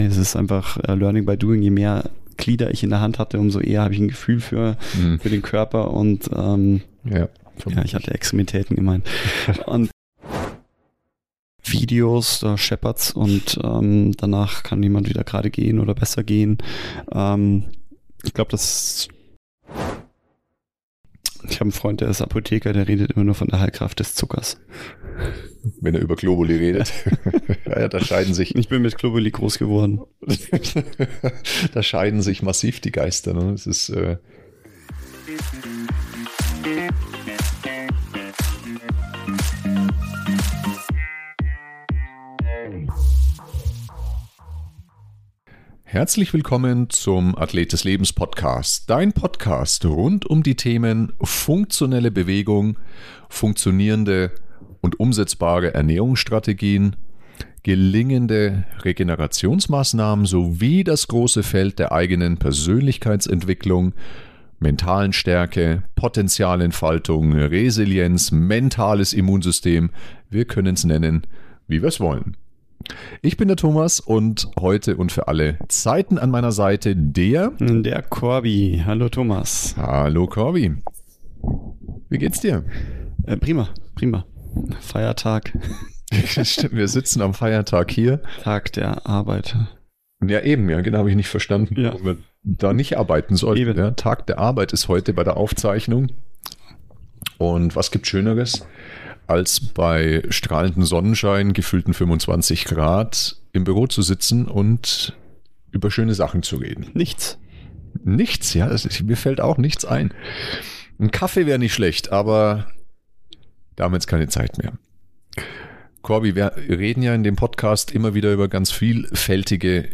Dieses Gespräch bietet nicht nur Fachwissen, sondern betont auch die Bedeutung von Empathie und individueller Patientenbetreuung.